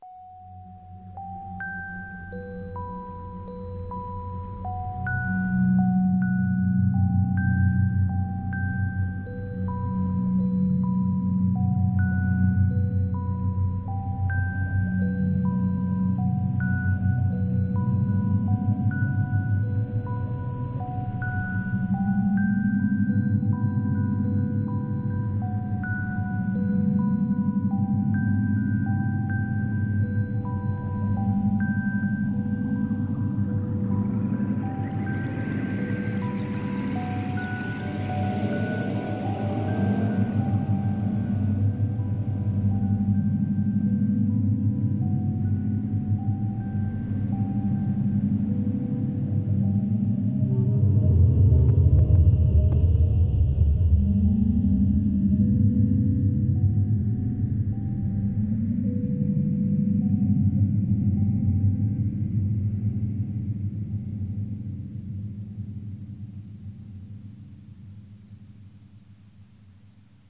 Winter a Short Theme Tune